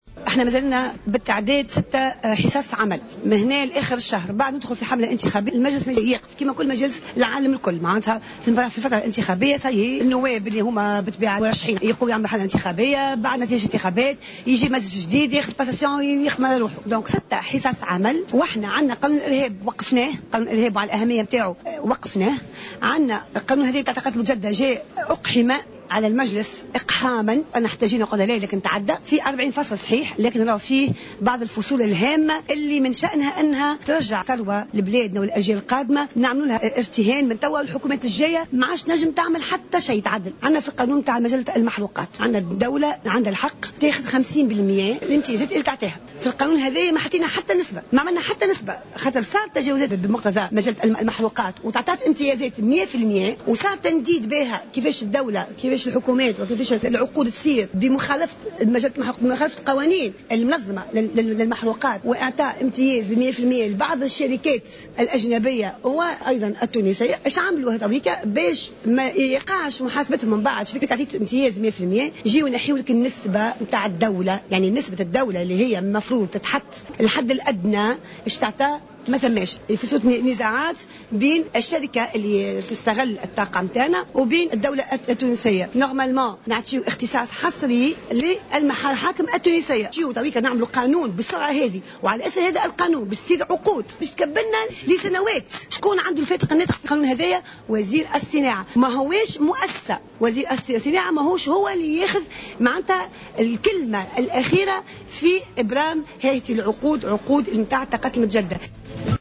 أكدت النائبة سامية عبو خلال جلسة عامة عقدها المجلس الوطني التأسيسي اليوم الثلاثاء أن مناقشة مشروع القانون المتعلق بانتاج الكهرباء من الطاقات المتجددة، في أجل لا يتجاوز 6 أيام،يعد أمرا مستحيلا.